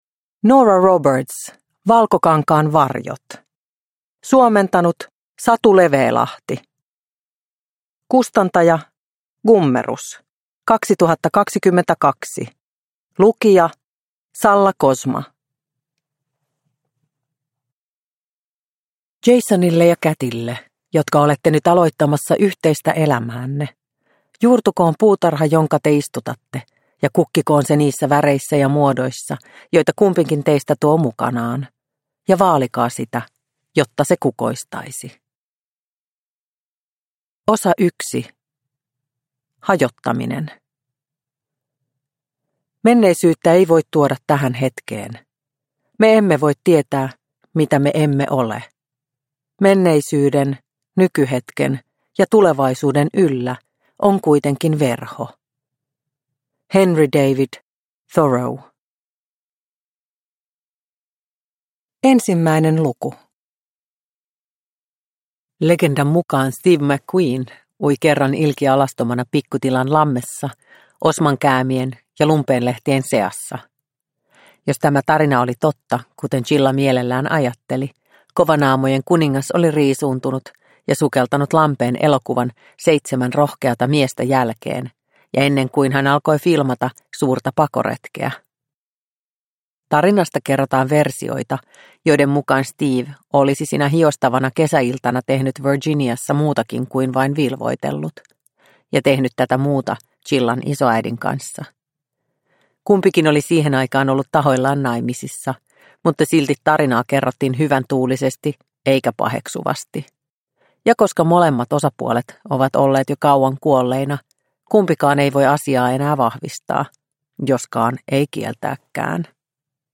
Valkokankaan varjot – Ljudbok – Laddas ner